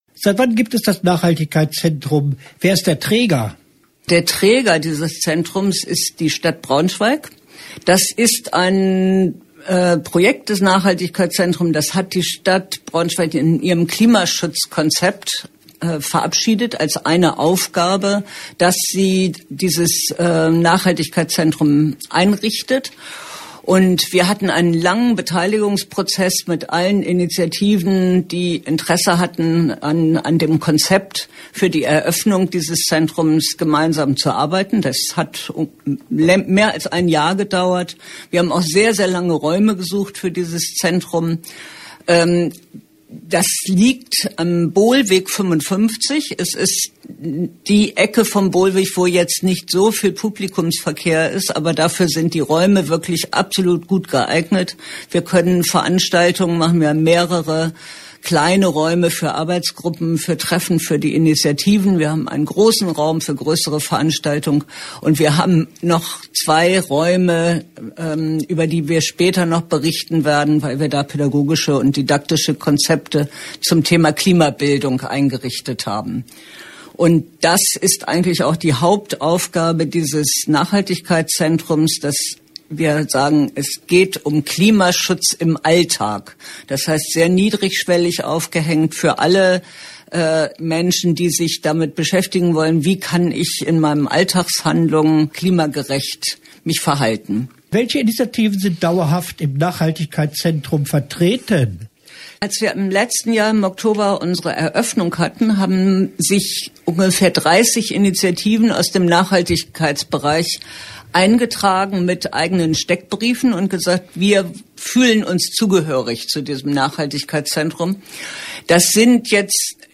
Interview-Nachhaltigkeitszentrum-BS_wa-.mp3